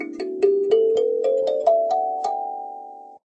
kalimba_scale_short.ogg